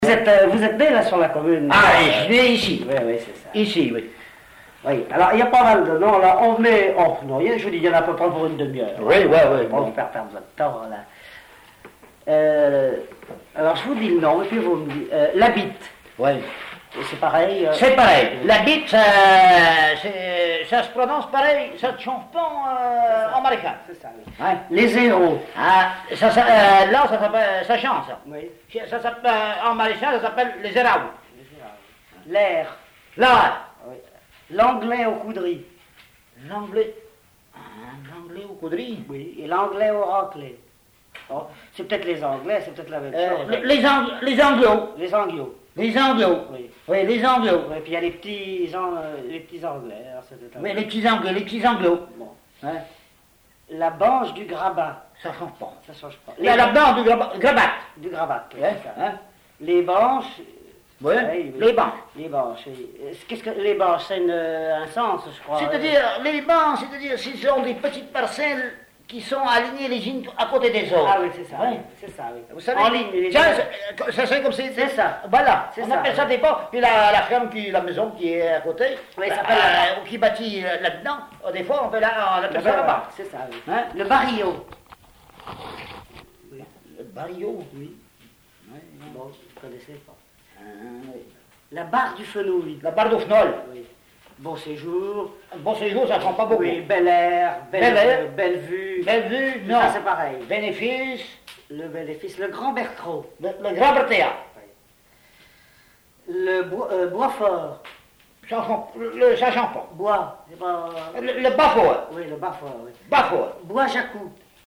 Localisation Perrier (Le)
Catégorie Témoignage